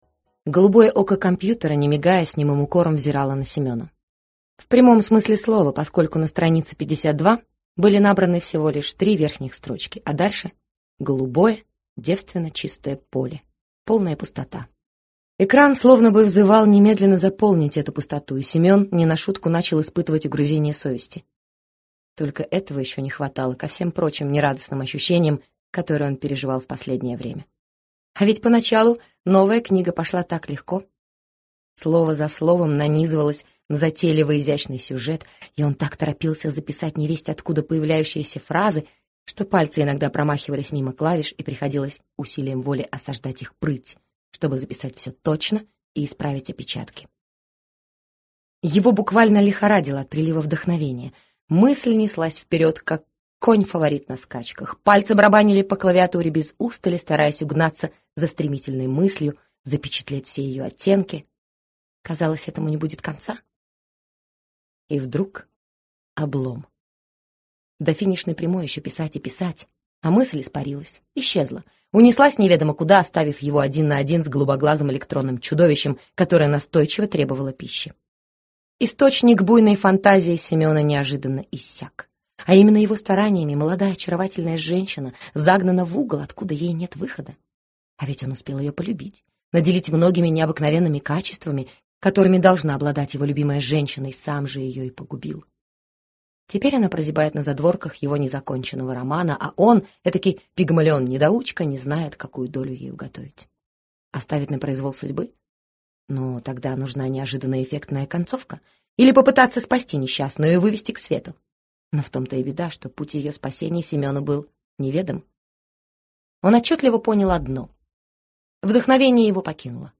Аудиокнига Повторный брак | Библиотека аудиокниг
Прослушать и бесплатно скачать фрагмент аудиокниги